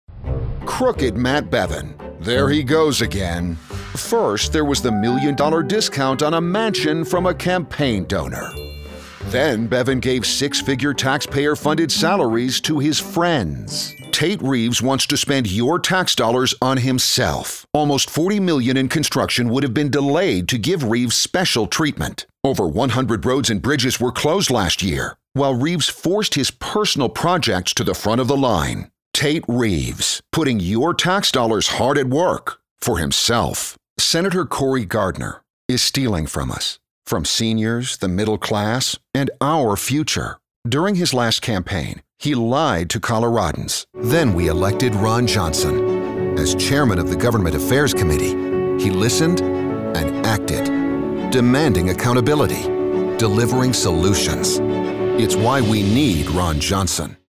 Voice Demos
Political Demo
political-demo-7Gr.mp3